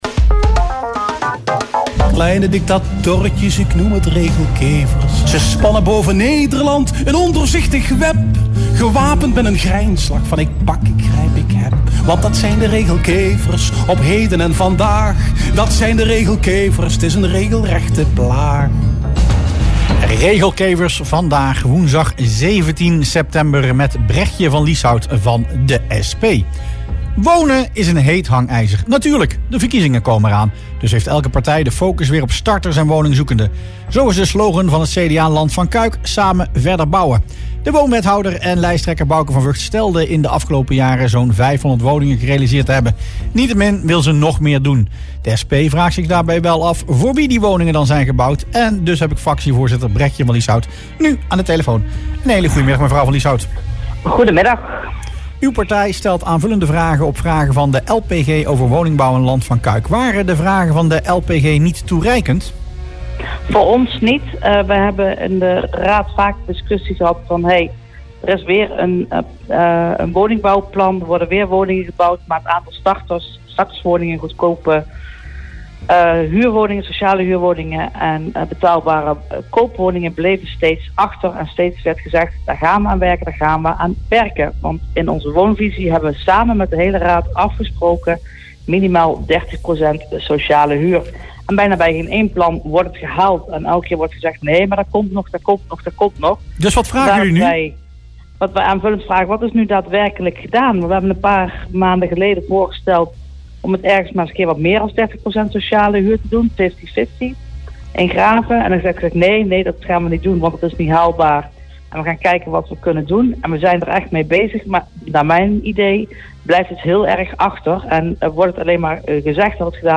Fractievoorzitter Bregje van Lieshout wees in het radioprogramma Rustplaats Lokkant op klachten over achterstallig onderhoud en slechte communicatie bij particuliere woningen in onder meer Cuijk.